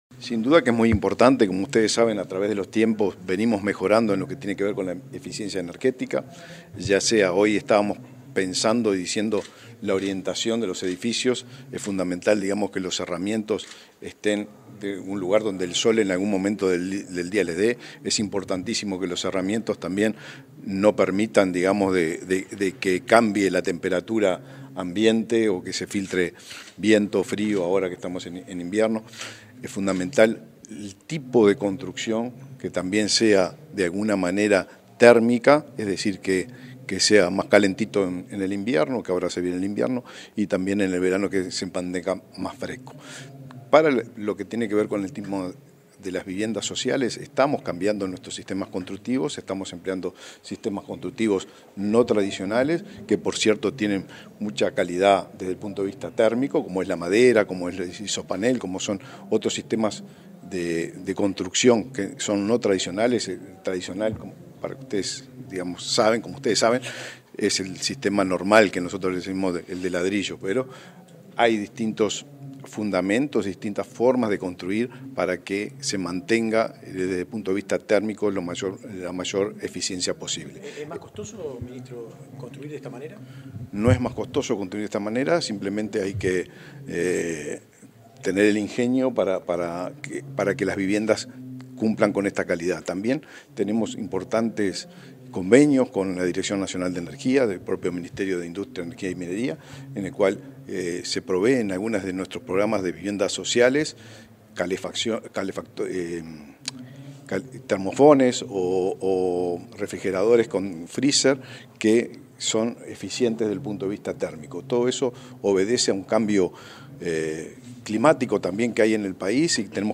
Declaraciones del ministro de Vivienda y Ordenamiento Territorial, Raúl Lozano
Declaraciones del ministro de Vivienda y Ordenamiento Territorial, Raúl Lozano 17/06/2024 Compartir Facebook X Copiar enlace WhatsApp LinkedIn Tras el lanzamiento del Modelo de Cálculo de Desempeño Energético para Viviendas, este 17 de junio, el ministro de Vivienda y Ordenamiento Territorial, Raúl Lozano, realizó declaraciones a la prensa.